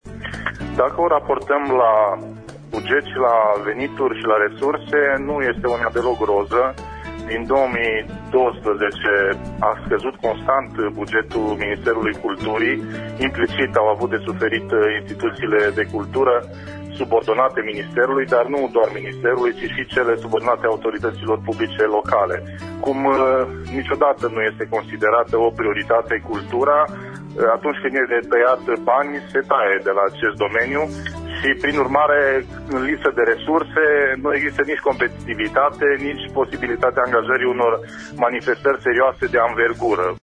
Aşa a afirmat, azi, în emisiunea „Pulsul Zilei”, senatorul PDL de Mureş, Marius Paşcan.